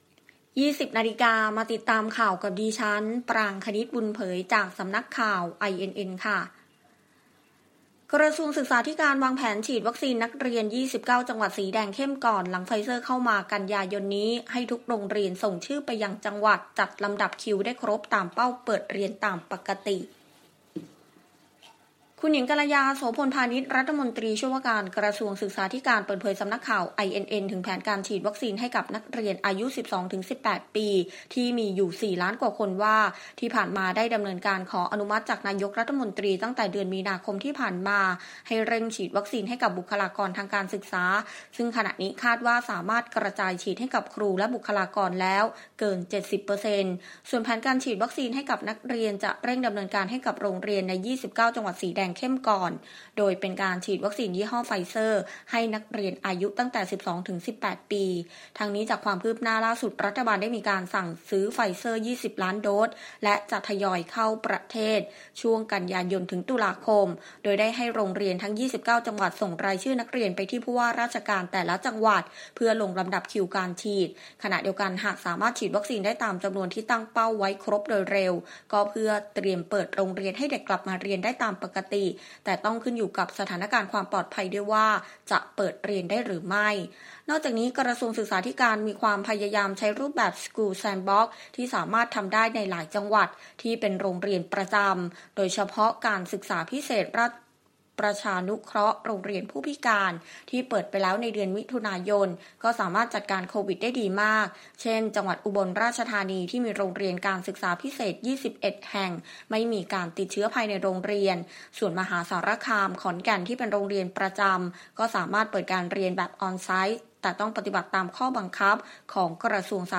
ข่าวต้นชั่วโมง 20.00 น.